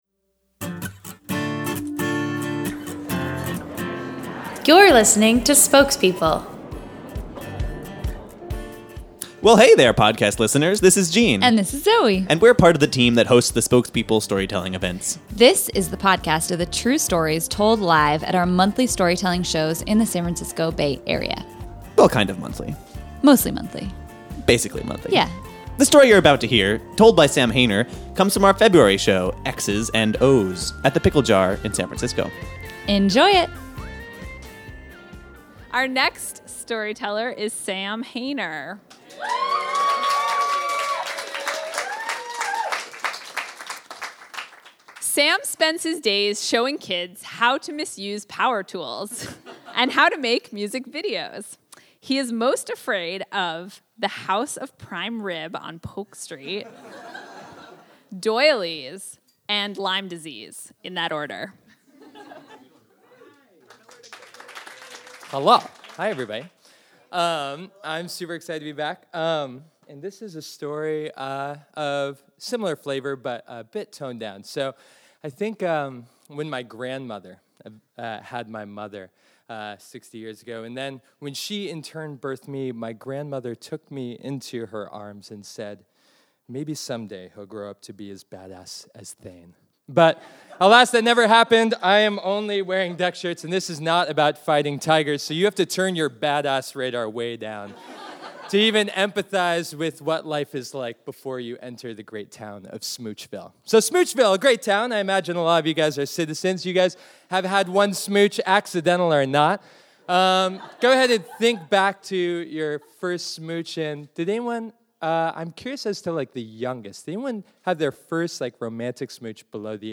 Our story’s hero plans a romantic beachside brunch to avoid conversation only to find his lips otherwise sealed. This story of a kiss inspired by the funny pages comes from our February 2014 show, “Exes and Ohhhhs.”